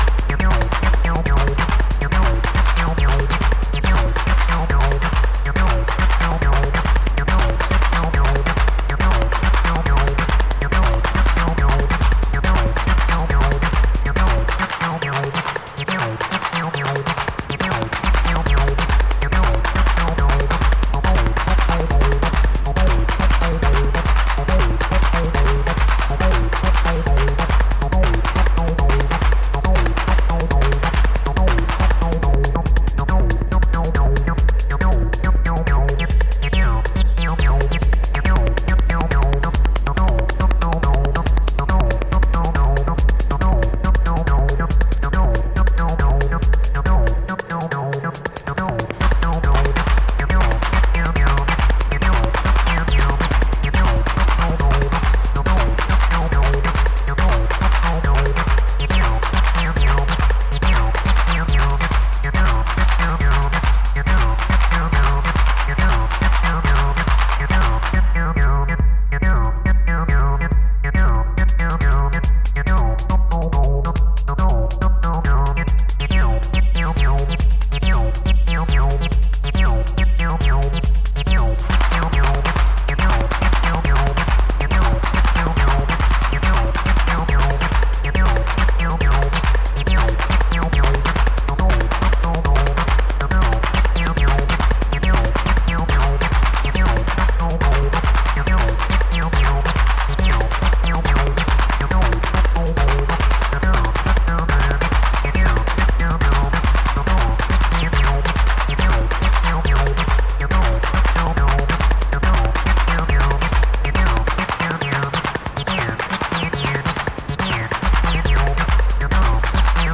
Deep housed trance on the A side at the right tempo.